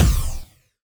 genericimpact.wav